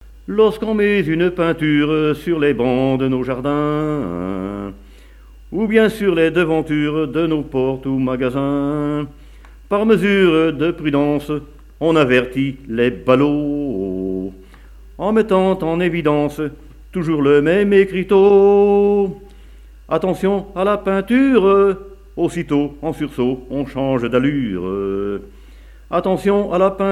Genre strophique
chansons
Pièce musicale inédite